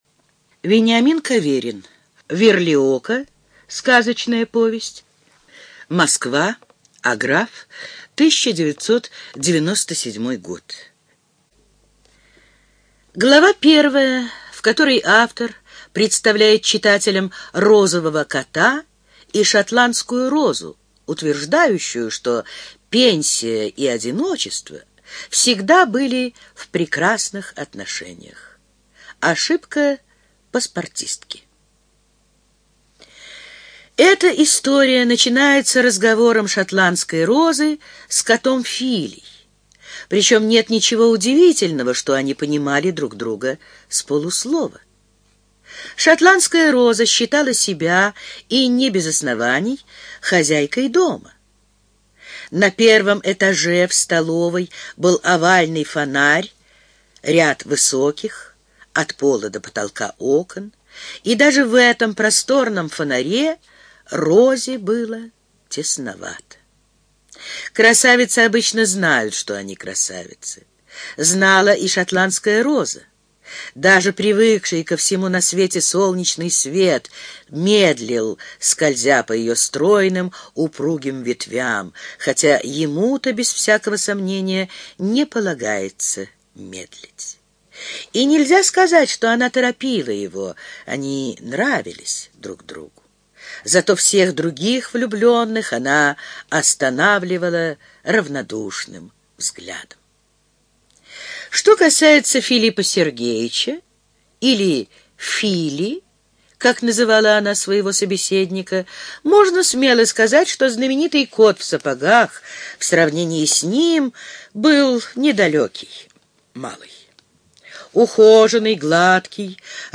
ЖанрДетская литература, Сказки
Студия звукозаписиЛогосвос